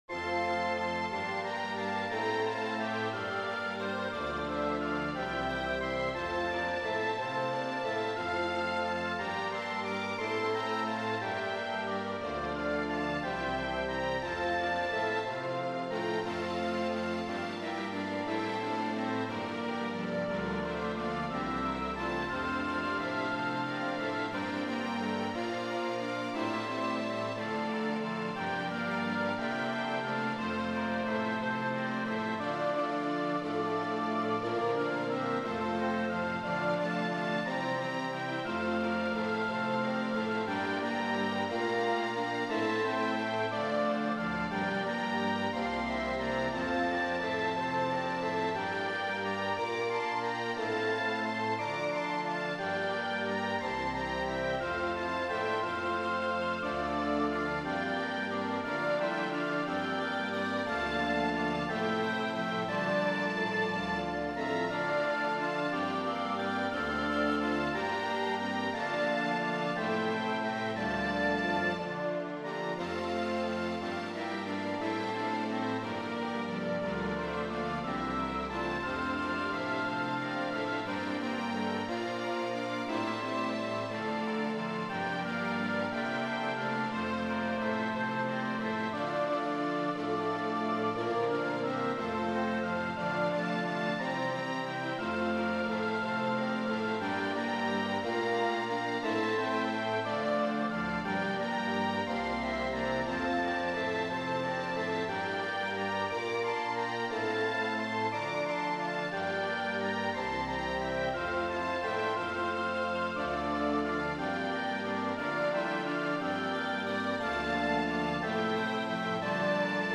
Piccolo, Flute, Bb Clarinet, Oboe, Bassoon
Trumpets 1,2; French Horn, Trombone 1, Trombone 2 Euphonium, Tuba
Violins 1,2; Viola, Cello, Bass
Alto, Tenor, Baritone Saxophone
Vihüela, Guitarrón
Percussion